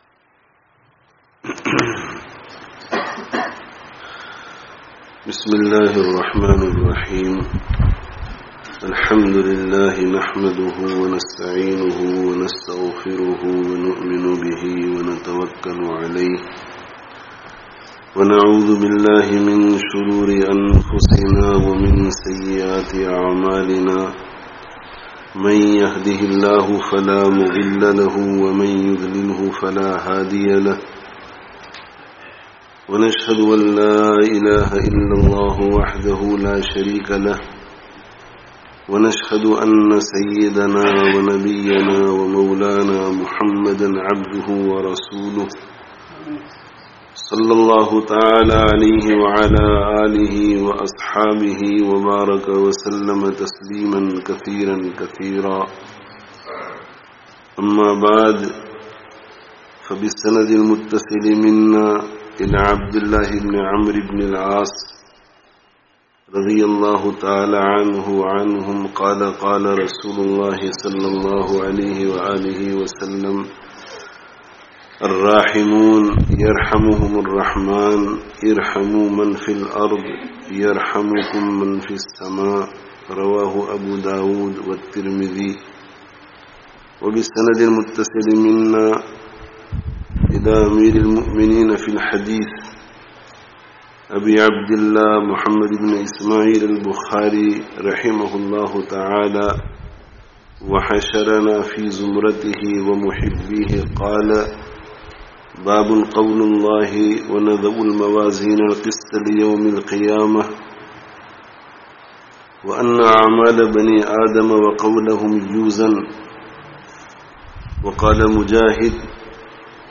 Khatme Bukhārī - 'Aqal Parastī Chor kar Taslīm kī Rāh Ikhtiyār Karo (Madrasah Rawdatus Sālihāt, Bolton 29/06/19)